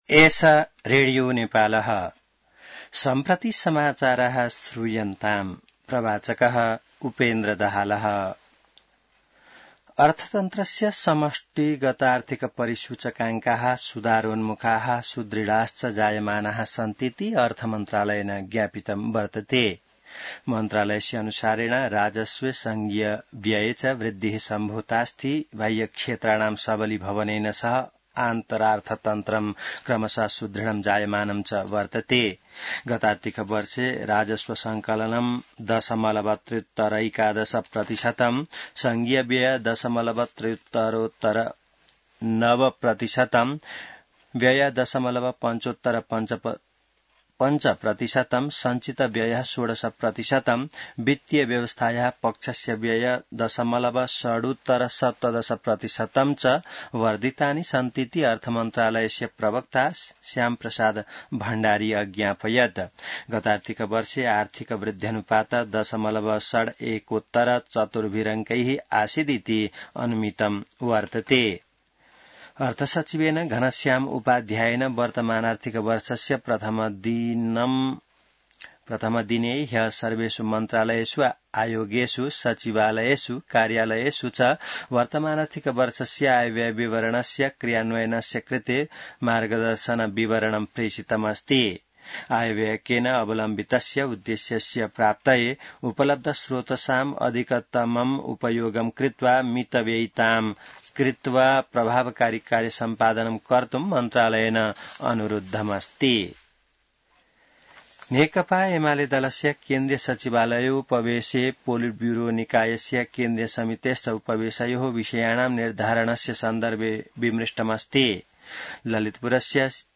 संस्कृत समाचार : २ साउन , २०८२